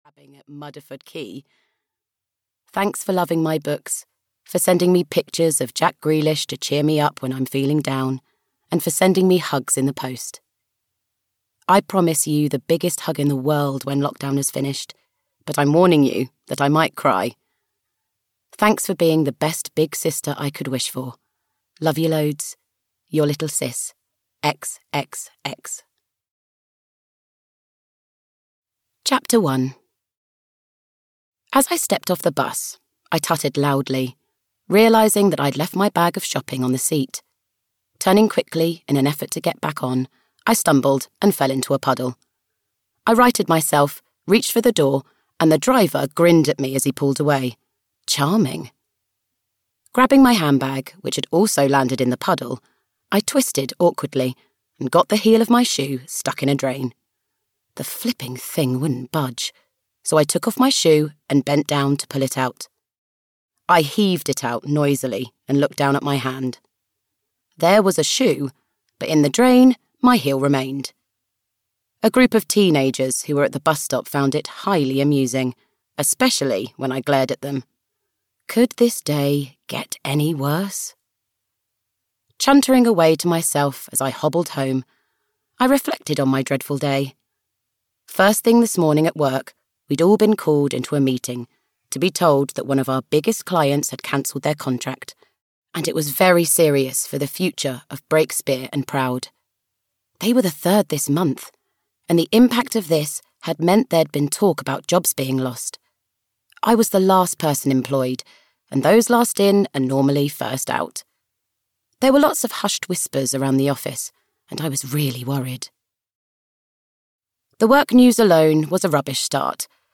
Moonlight Over Muddleford Cove (EN) audiokniha
Ukázka z knihy